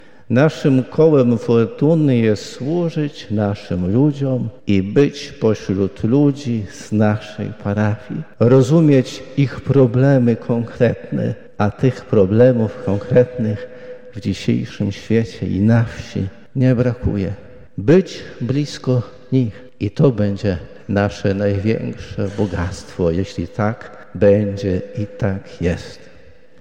,,Zadaniem kapłanów jest to, aby nieść światło i nadzieję” mówił w homilii podczas Mszy św. Krzyżma w Katedrze pw. św. Michała Archanioła biskup Janusz Stepnowski, pasterz Kościoła Łomżyńskiego.